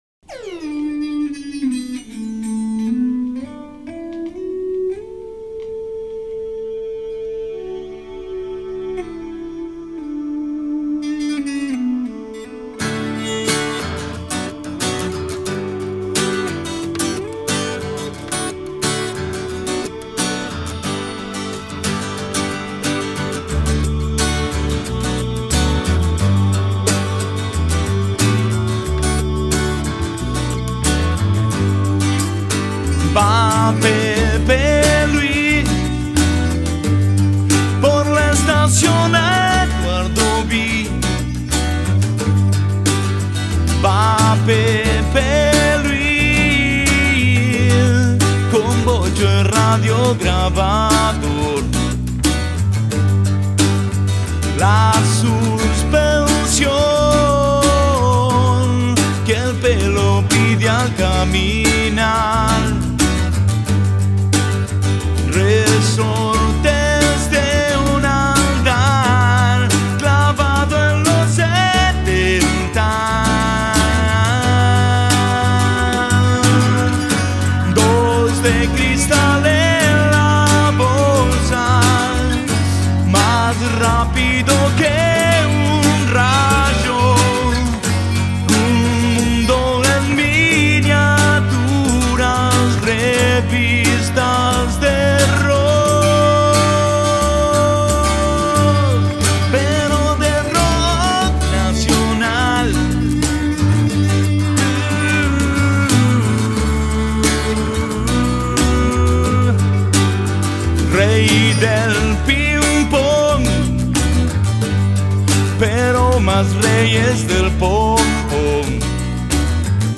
Carpeta: Rock argentino mp3